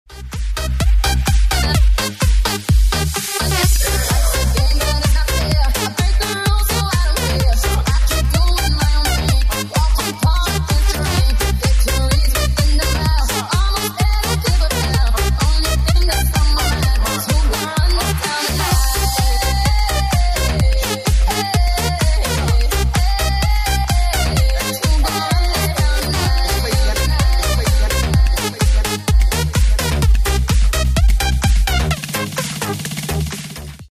• Качество: 128, Stereo
громкие
женский вокал
dance
Electronic
электронная музыка
быстрые
electro house
bounce